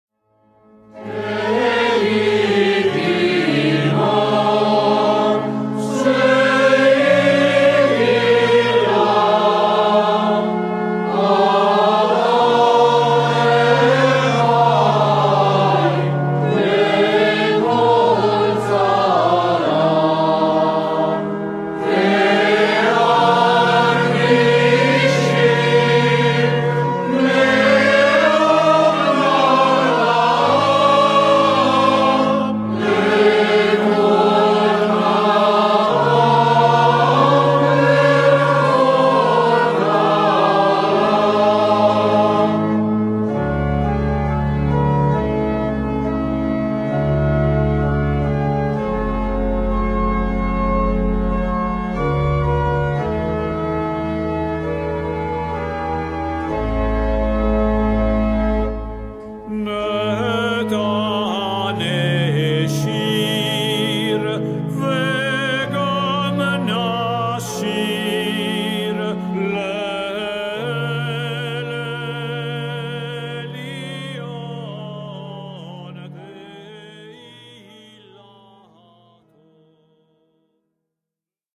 Coro e solista